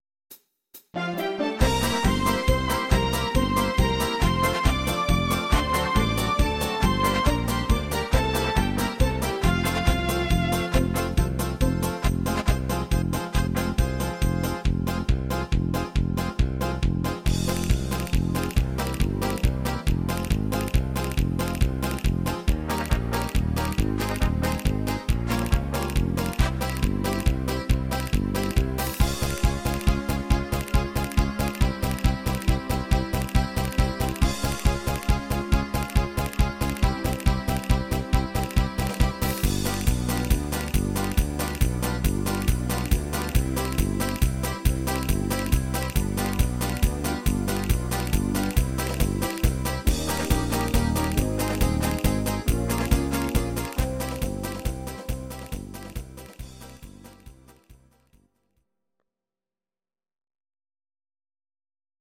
These are MP3 versions of our MIDI file catalogue.
Please note: no vocals and no karaoke included.
Paso Doble